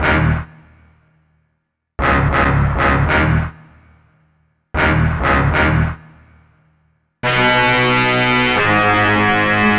Rock Star - Horns Stab.wav